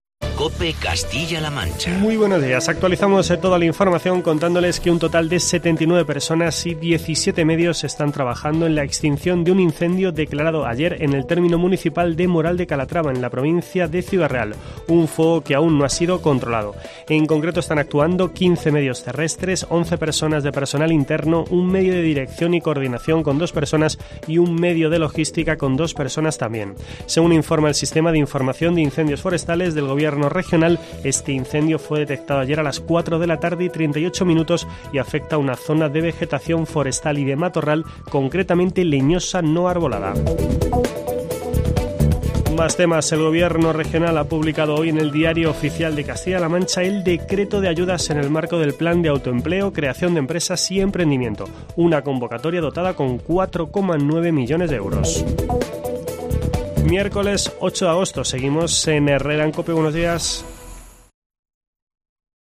Boletín informativo de COPE Castilla-La Mancha